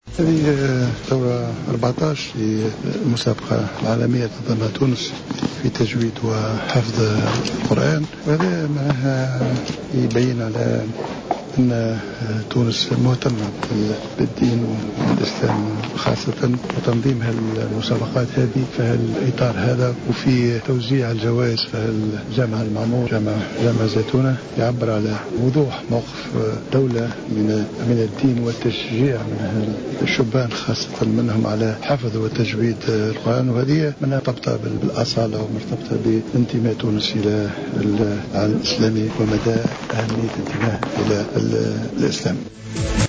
أشرف رئيس الحكومة الحبيب الصيد اليوم الخميس، على حفل اختتام جائزة تونس العالمية الـ14 في حفظ القرآن الكريم وتجويده بجامع الزيتونة المعمور.
وأكد الصيد في تصريح للجوهرة أف أم أن تنظيم هذا النوع من المسابقات يعبر عن وضوح موقف الدولة وتشبثها بهويتها العربية الإسلامية من خلال تشجيع الشباب على حفظ القرآن الكريم وتجويده.